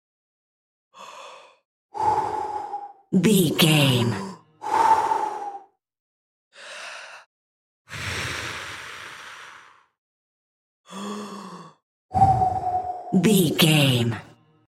Human blow x4
Sound Effects
Atonal
blowing